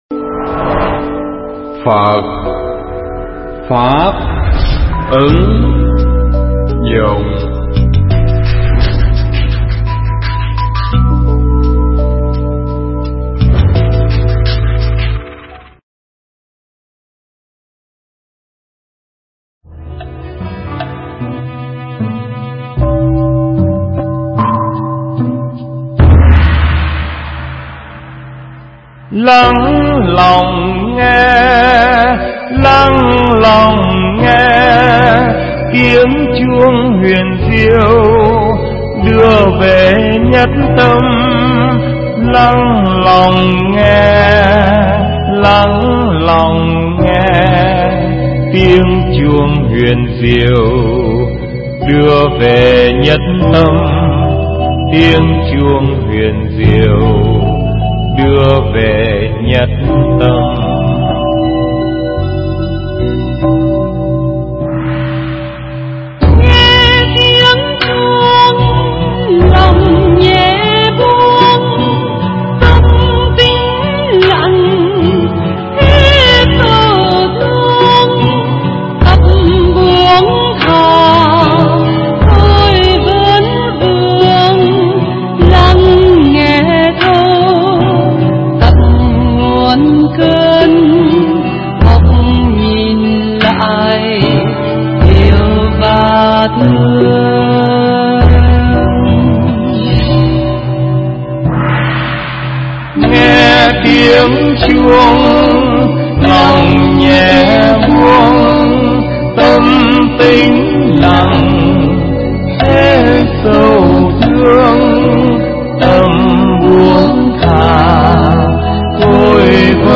Tải mp3 pháp thoại Kinh Trung Bộ